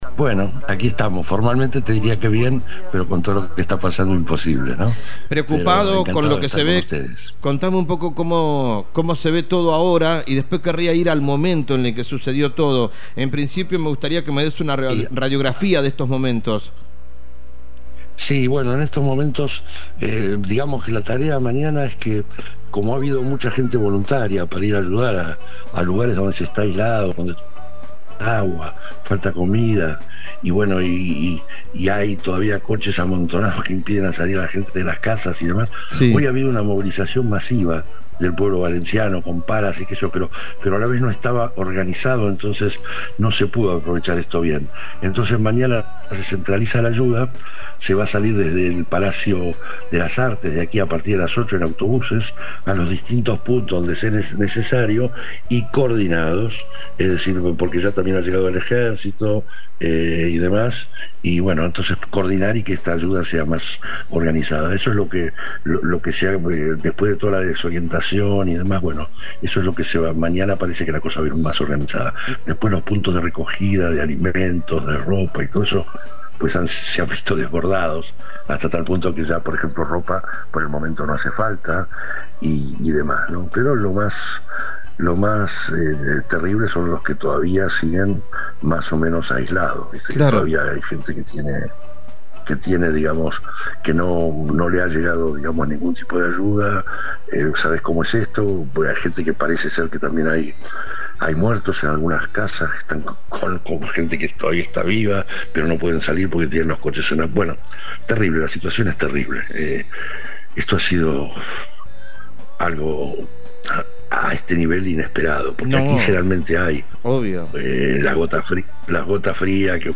ARGENTINO RADICADO EN ESPAÑA – QUIEN NOS HABLA DE LAS INUNDACIONES DE VALENCIA